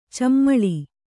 ♪ cammaḷi